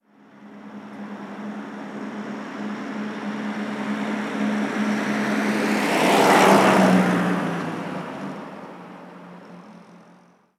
Coche pasando a velocidad normal 2
coche
Sonidos: Transportes